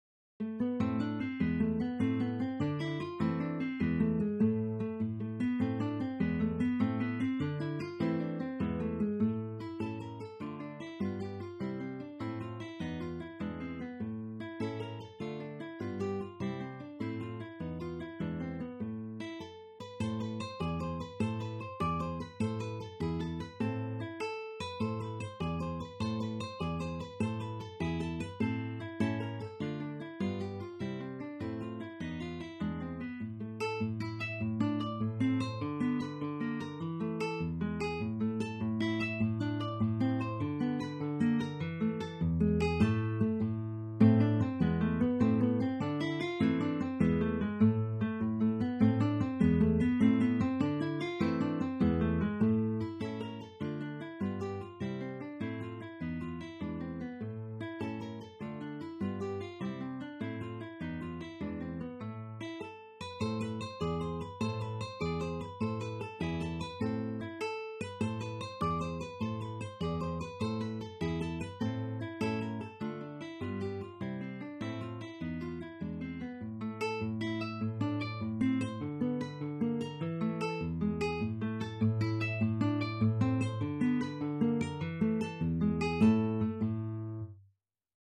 Insieme scrissero la raccolta di brani per chitarra ALBUMSBLADE, la cui parte preminente è di Frederik.